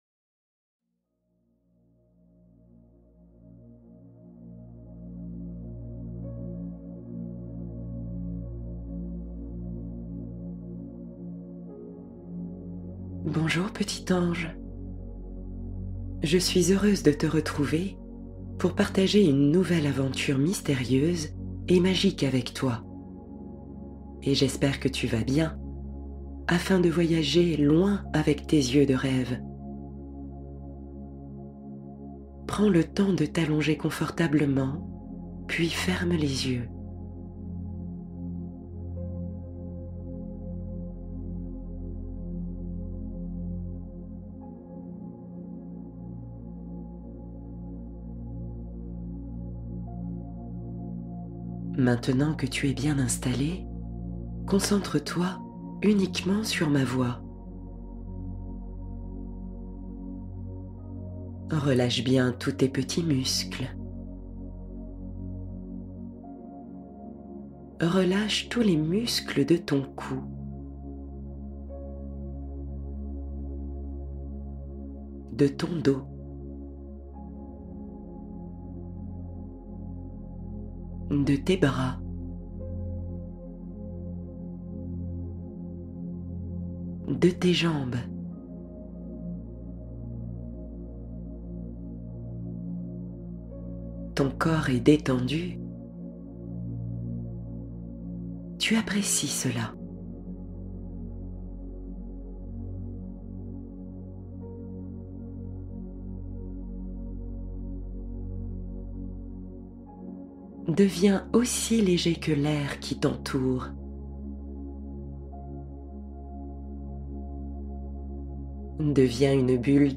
Conte du soir — Voyage au clair de lune pour s’endormir paisiblement